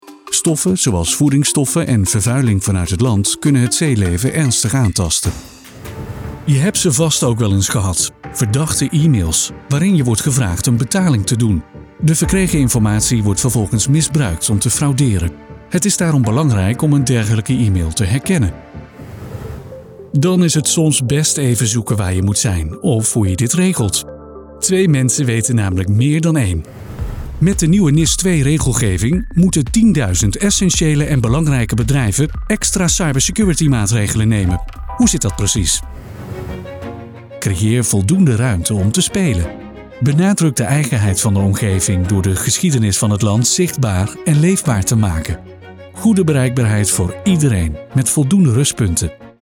Zuverlässig, Freundlich, Corporate
Unternehmensvideo